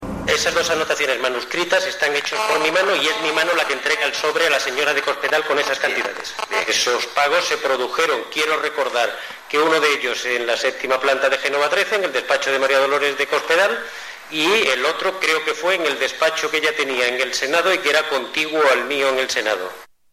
Luis Bárcenas declara por videoconferencia en el juicio por la demanda de protección del honor interpuesta por M.D. de Cospedal cómo le entregó a a ésta en dos ocasiones dos osbres con dinero 18/10/2013